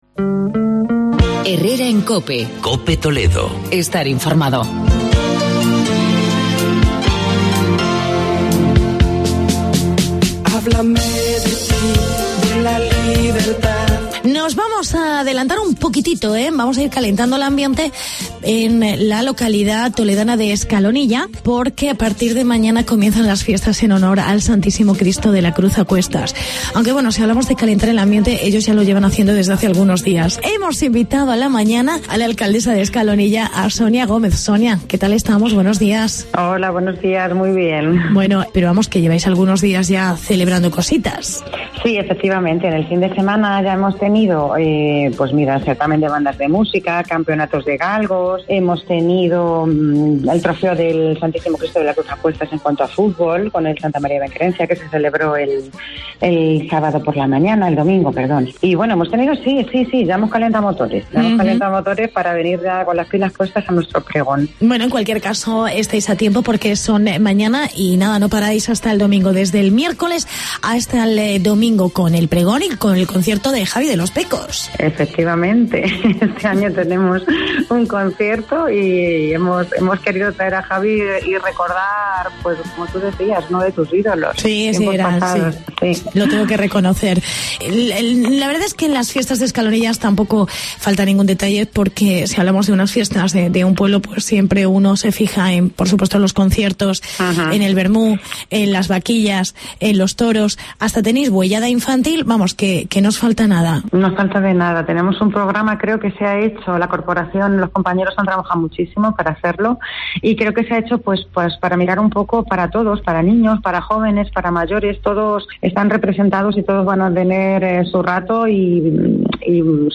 Fiestas en Escalonilla. Hablamos con la alcaldesa: Sonia Gómez